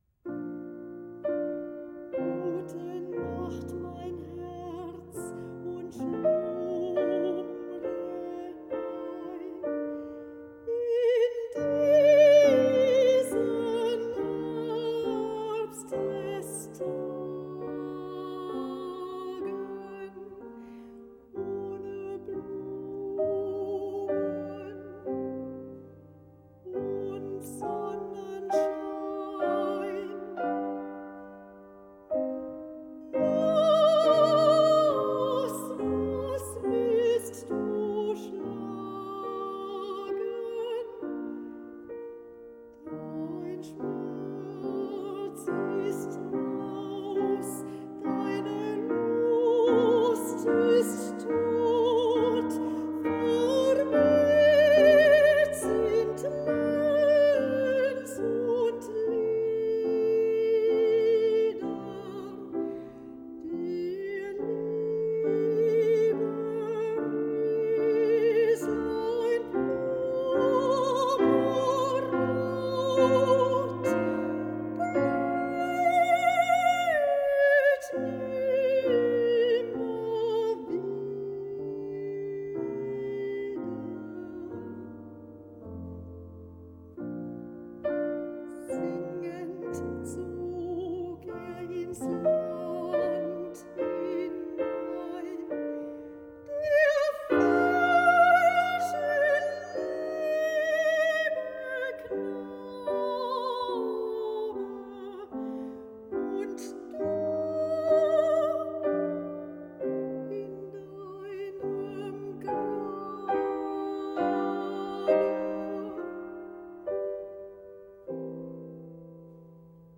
Besetzung: Singstimme und Klavier
für eine Singstimme mit Klavierbegleitung